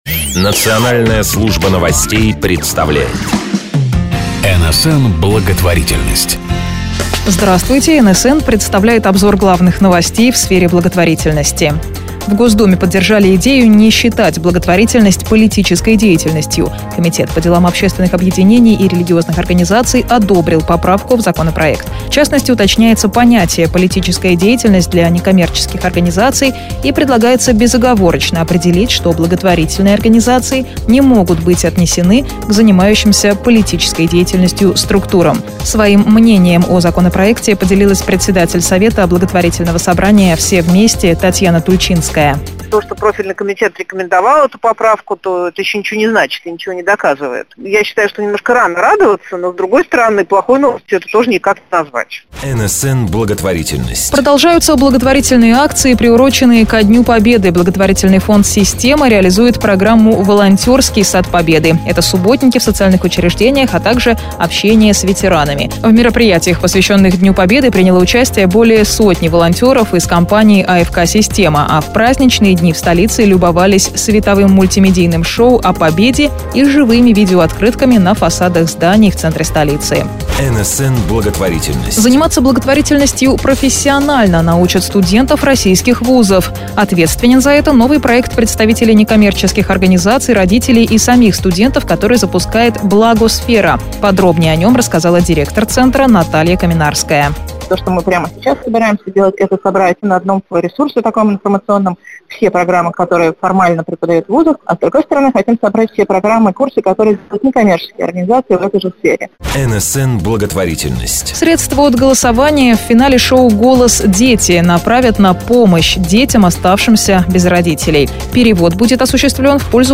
Специальный выпуск новостей «НСН-Благотворительность» содержит топ-5 новостей из сферы благотворительности, которые выходят каждый четверг в 19.00 для слушателей радиостанций «НАШЕ Радио», ROCK FM, Best FM, Radio JAZZ.